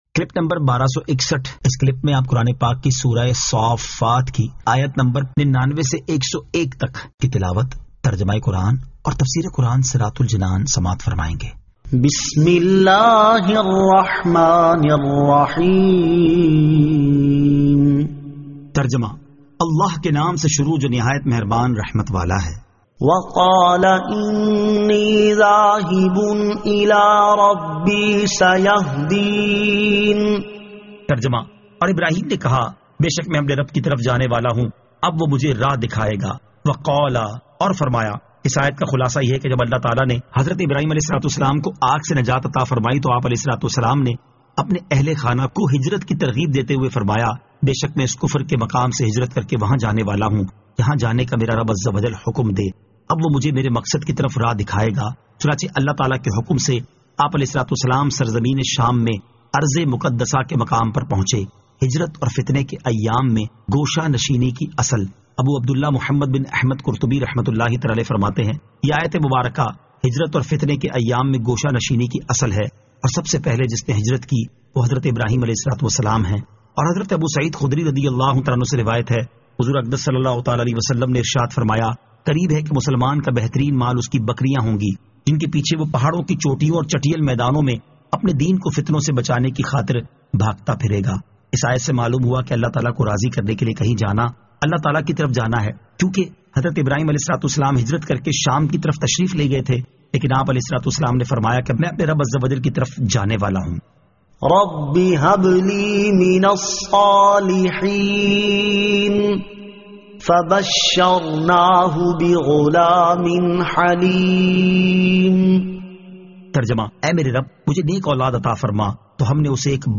Surah As-Saaffat 99 To 101 Tilawat , Tarjama , Tafseer